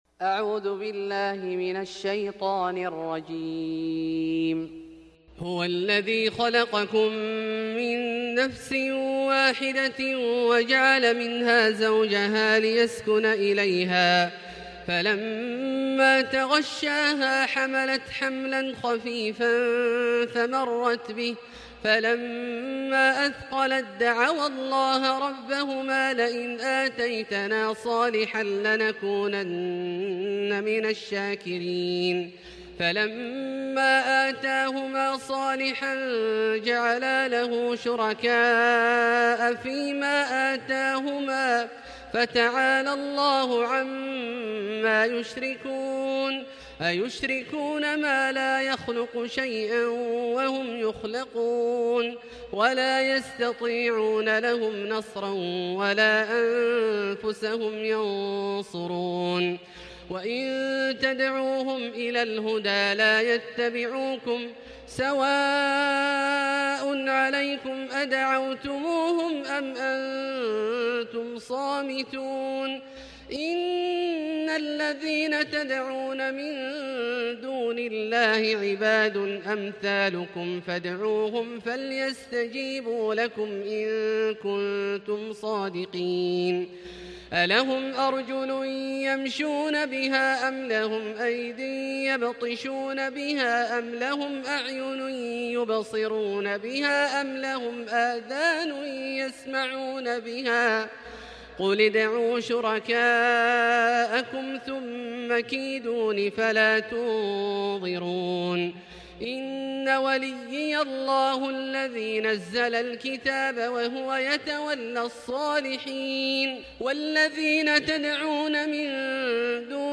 صلاة التهجد 29 رمضان 1440هـ من سورتي الأعراف (189-206) والأنفال (1-40) Tahajjud 29 st night Ramadan 1440H from Surah Al-A’raf and Al-Anfal > تراويح الحرم المكي عام 1440 🕋 > التراويح - تلاوات الحرمين